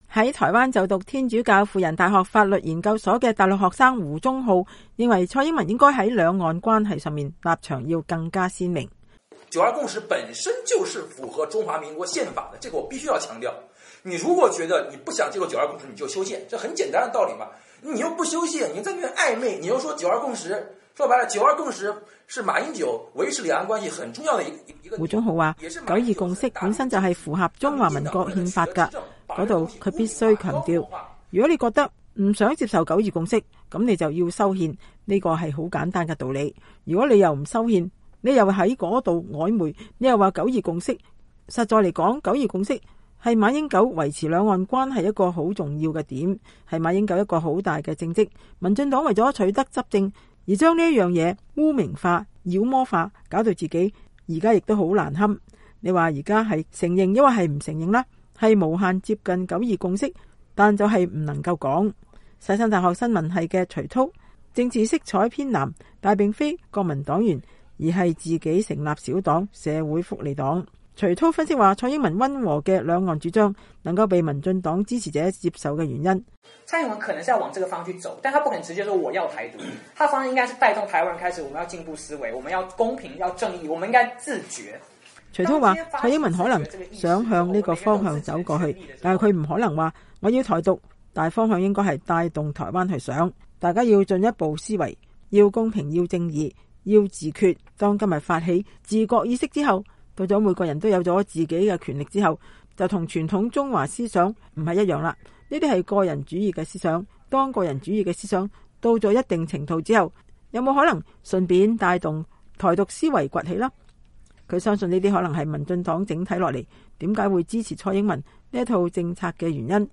來自兩岸四地的學生議論蔡英文與兩岸